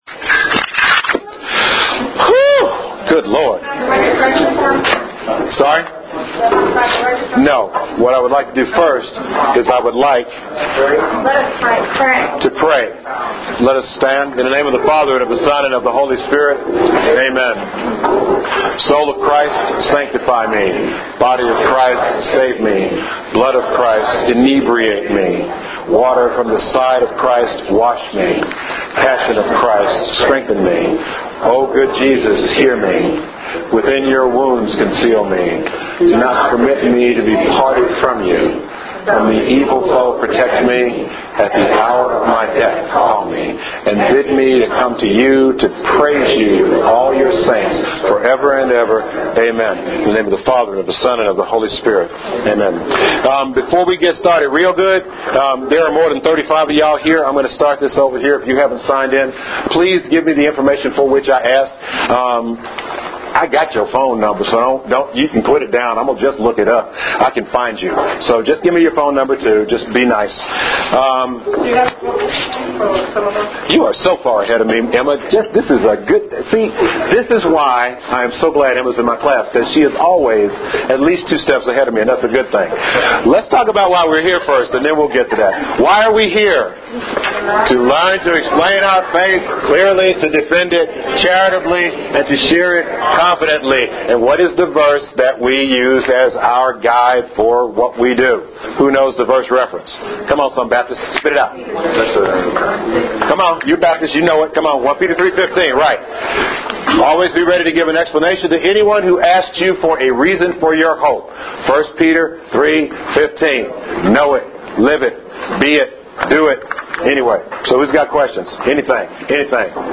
OK. The second class (Jan 24) audio is here.